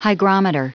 Prononciation audio / Fichier audio de HYGROMETER en anglais
Prononciation du mot hygrometer en anglais (fichier audio)